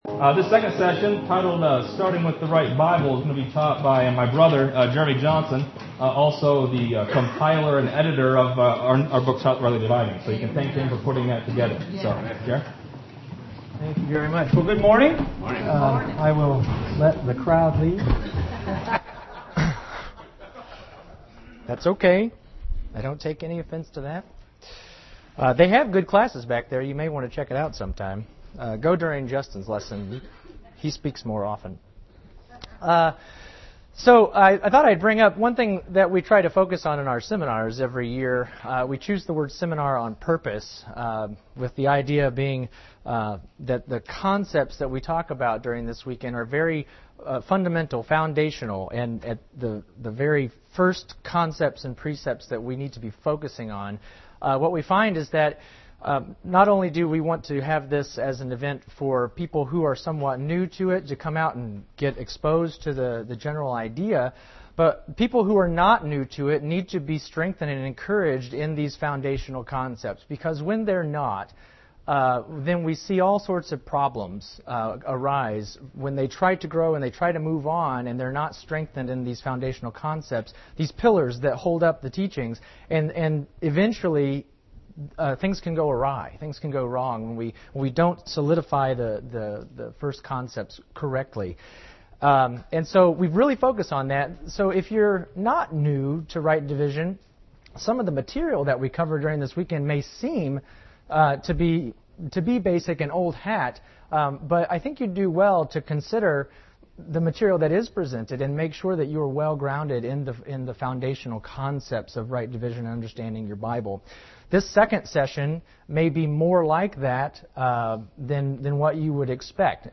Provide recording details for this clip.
Seminar 2015: 02 – Start with the Right Bible